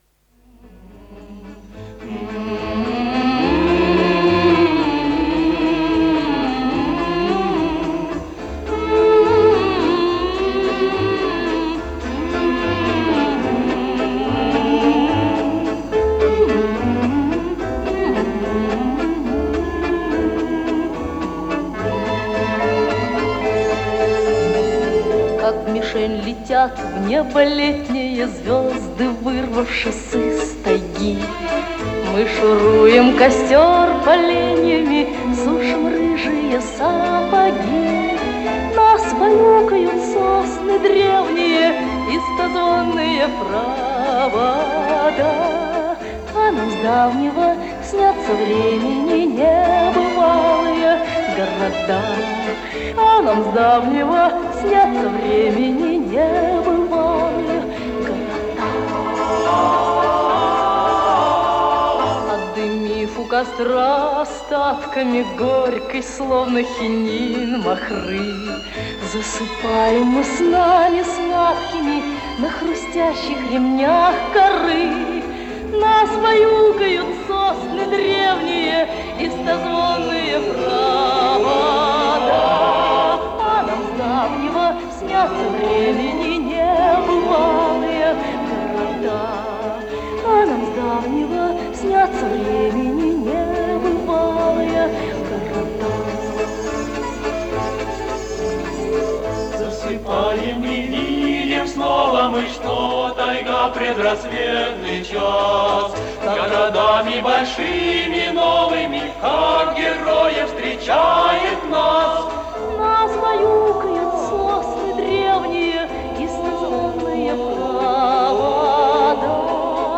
Интересно откуда качественная запись?